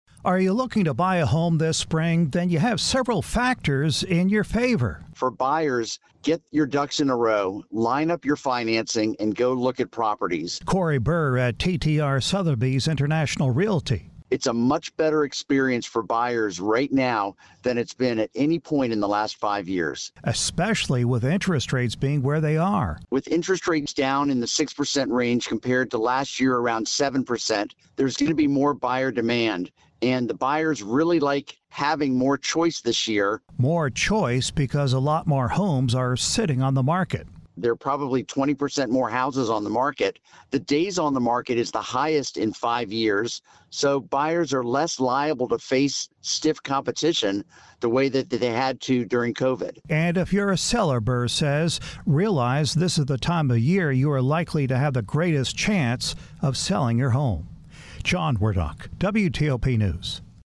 reports there are a few things breaking in your favor if you're looking to buy a home this spring.